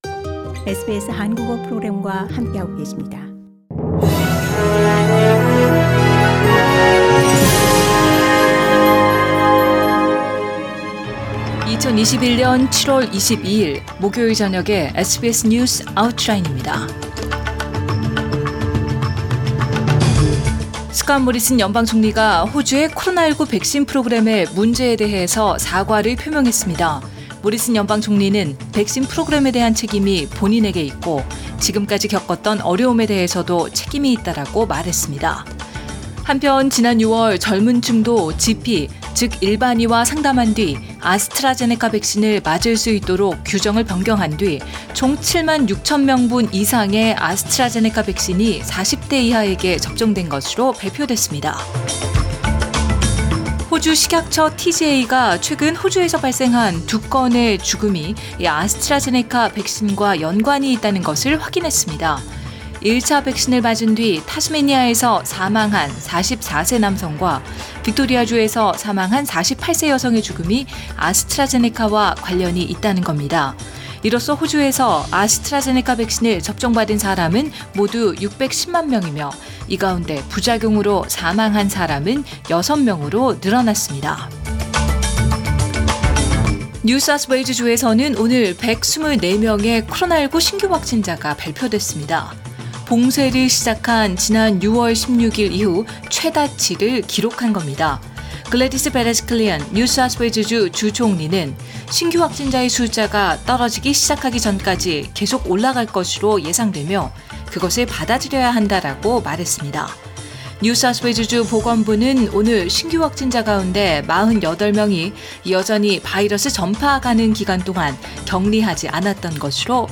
2021년 7월 22일 목요일 저녁의 SBS 뉴스 아우트라인입니다.